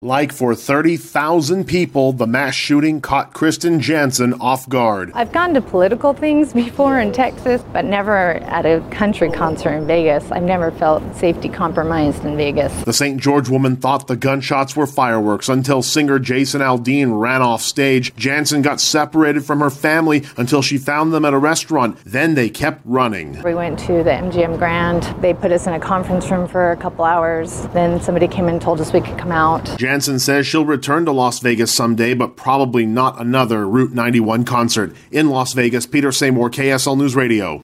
A Saint George woman recounts how she survived the mass shooting at the Route 91 Concert in Las Vegas.